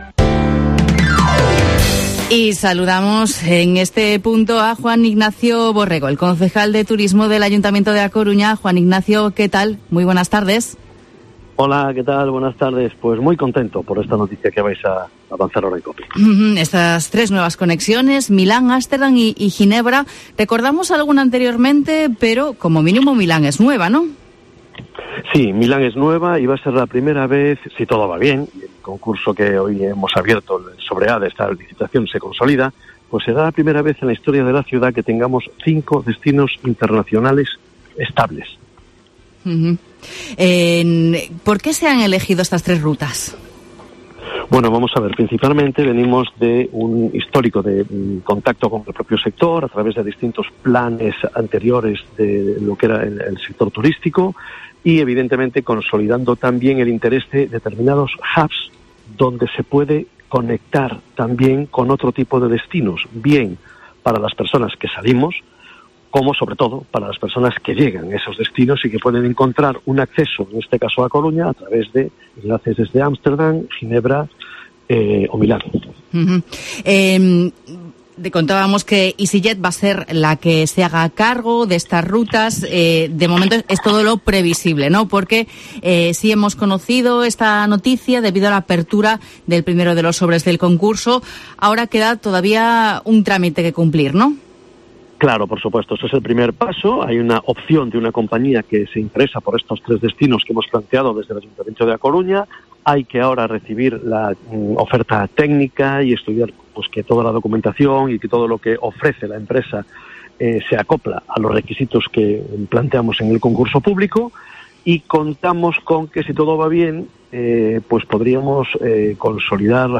El concejal de Turismo de A Coruña, Juan Ignacio Borrego, explicaba en COPE Coruña que entre los objetivos inmediatos para Alvedro están recuperar los enlaces de Lisboa y Sevilla
Juan Ignacio Borrego, concejal de Turismo de A Coruña, sobre las nuevas conexiones internacionales de Alvedro